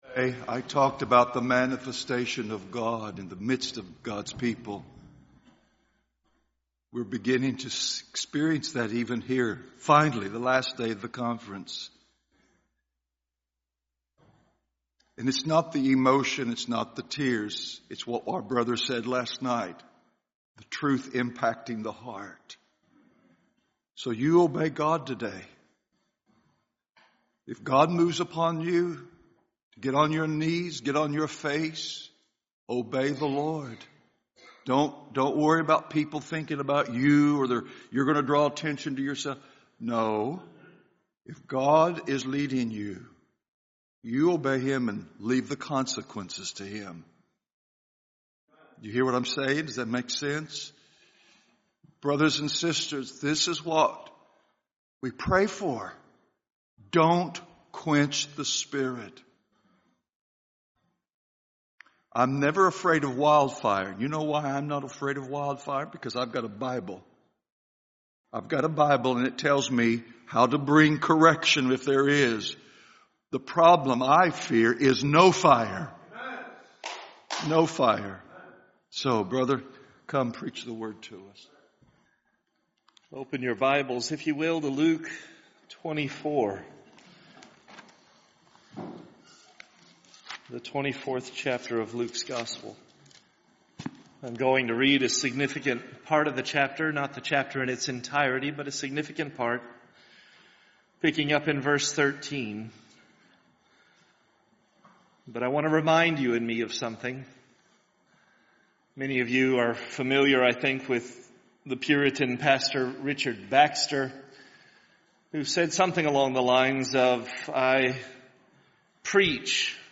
This sermon dives into the heart of Christ's desire for fellowship with us, exploring the depth of our responsibility to seek Him persistently.